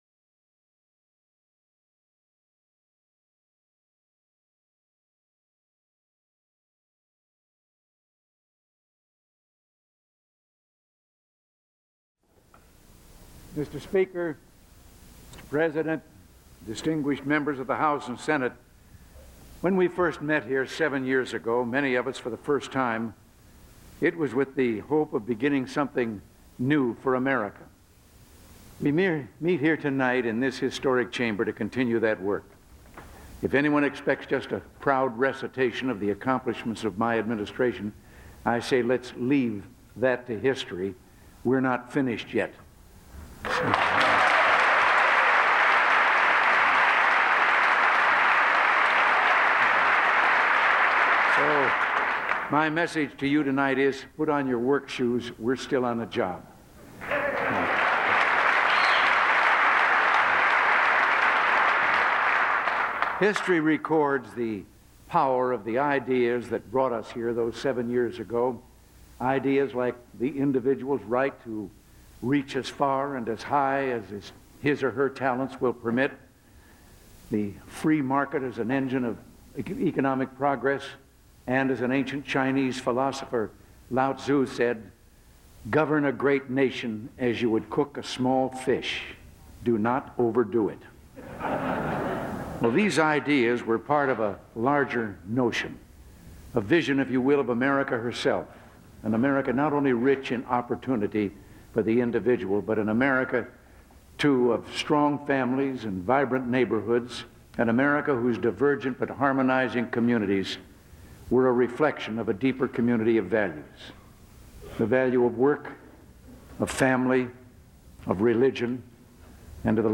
January 25, 1988: State of the Union Address